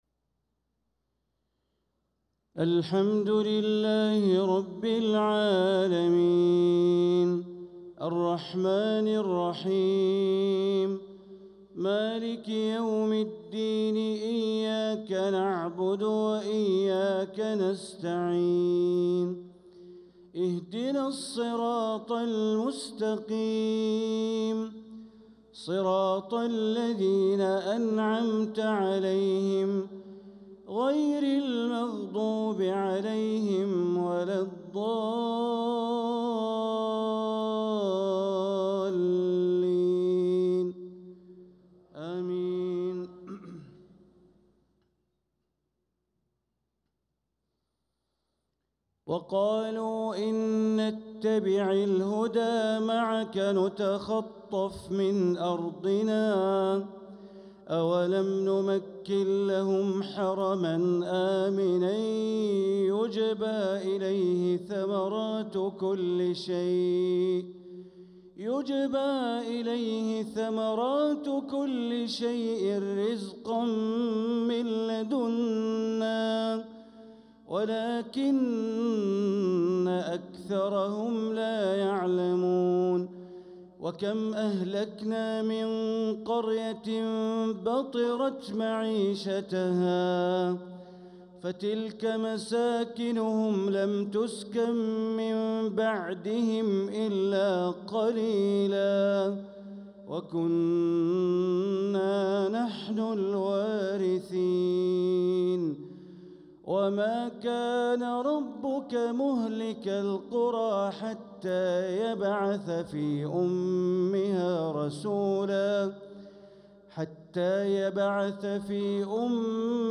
صلاة العشاء للقارئ بندر بليلة 5 صفر 1446 هـ
تِلَاوَات الْحَرَمَيْن .